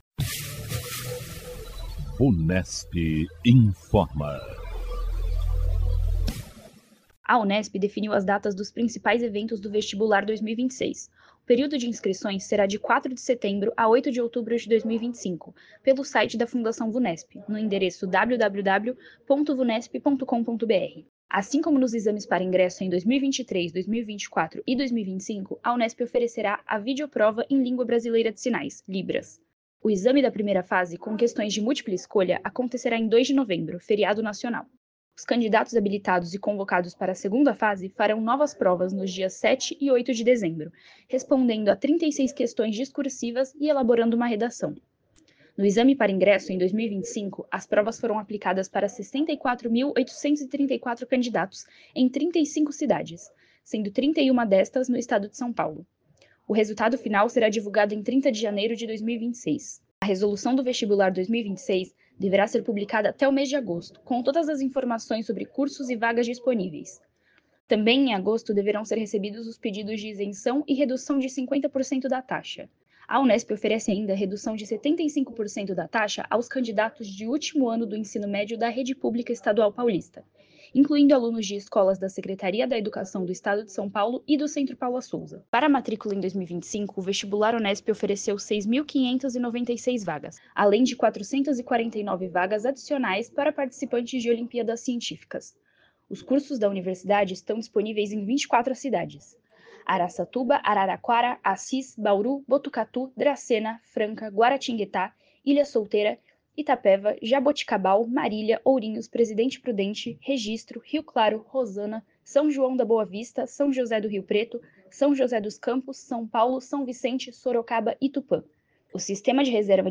A equipe de jornalistas da Vunesp apresenta as últimas informações sobre concursos, vestibulares e avaliações feitas pela Instituição.